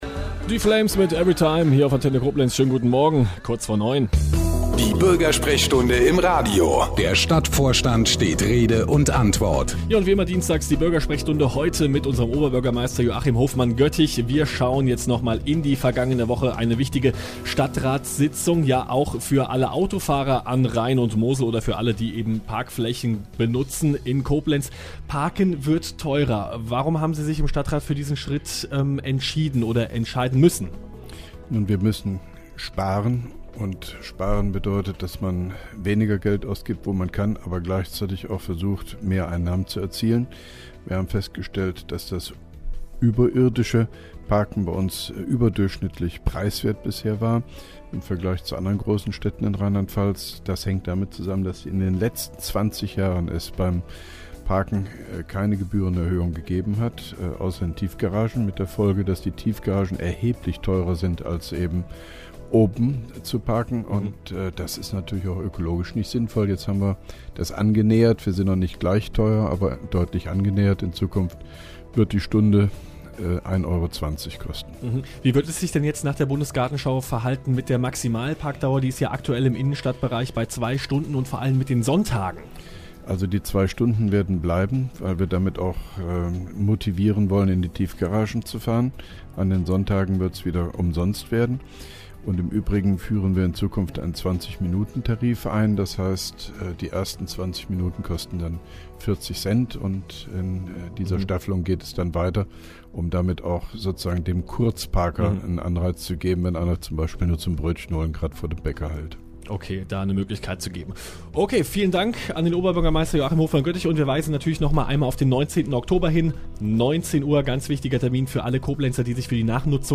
(3) Koblenzer Radio-Bürgersprechstunde mit OB Hofmann-Göttig 04.10.2011